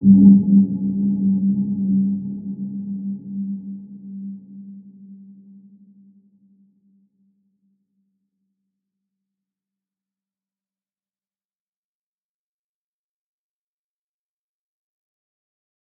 Dark-Soft-Impact-G3-p.wav